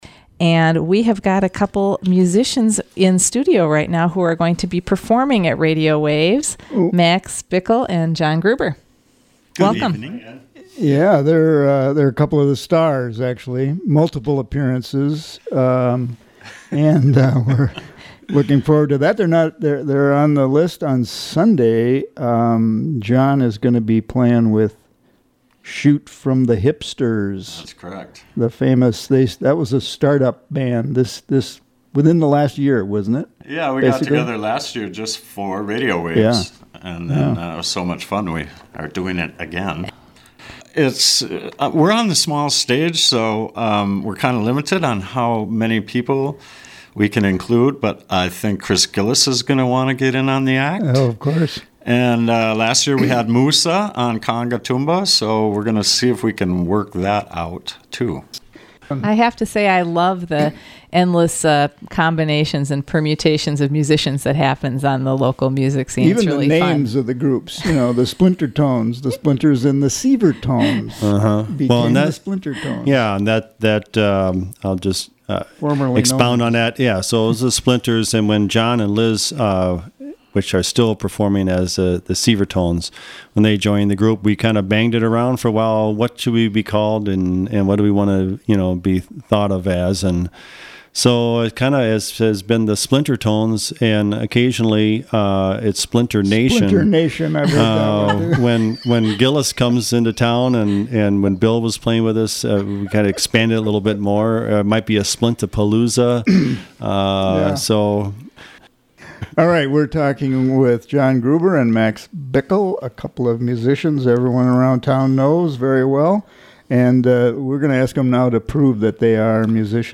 guitar
violin
Program: Live Music Archive The Roadhouse